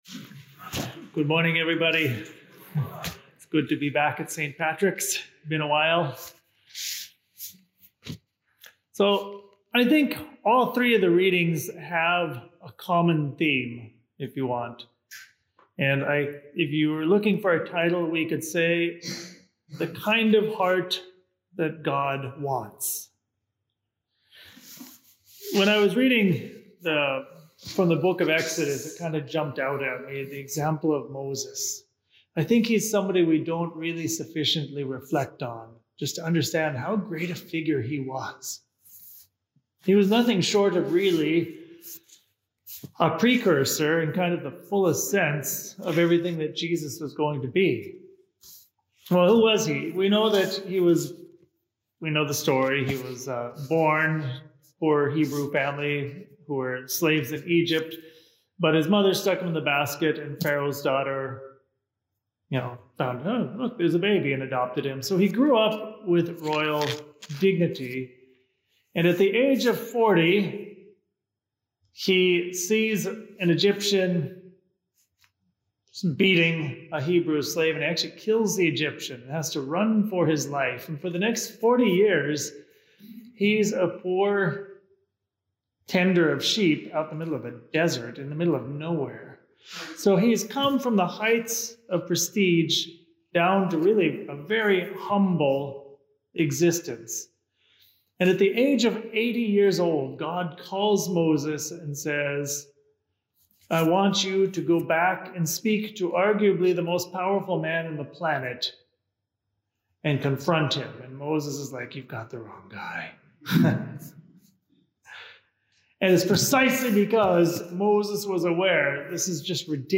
24th Sunday in Ordinary Time Homily